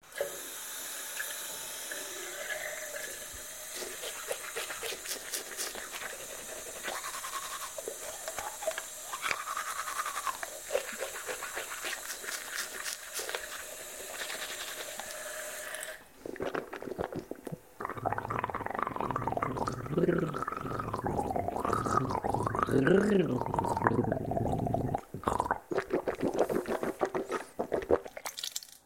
Звуки чистки зубов
чистит полощет выплевывает